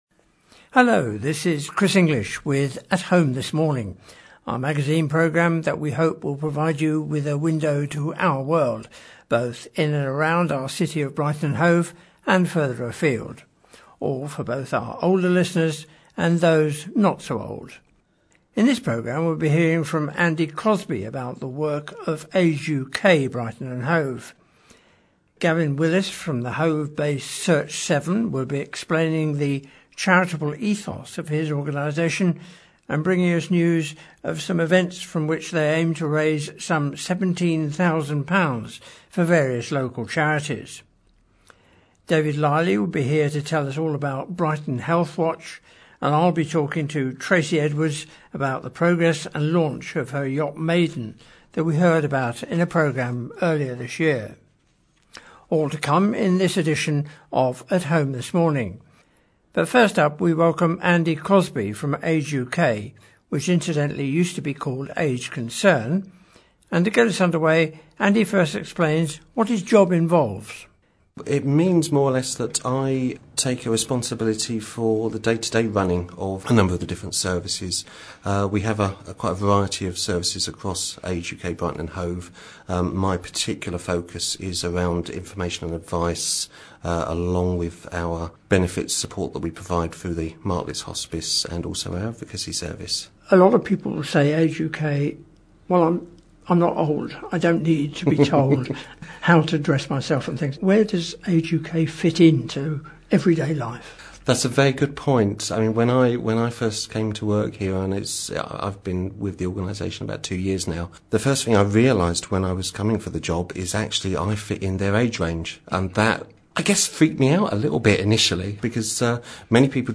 You can download edited versions of some of our recent programmes below (the editing has removed the music content of the original shows for copyright reasons), or listen to them by clicking on the arrow.
A community magazine radio programme for Brighton & Hove